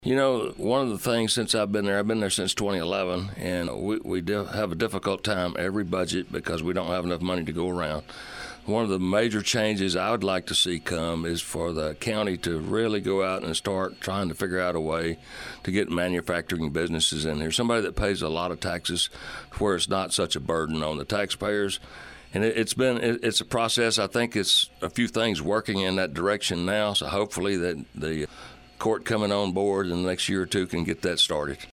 Our guest on our Around Town public affairs program broadcast over the weekend was Walker County Precinct 2 Commissioner Ronnie White.